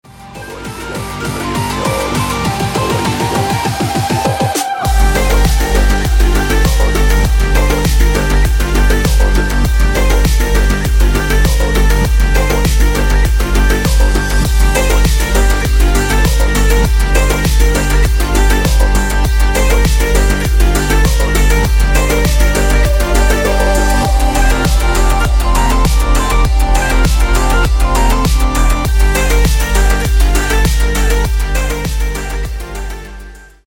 Клубные Рингтоны » # Восточные Рингтоны
Танцевальные Рингтоны » # Рингтоны Электроника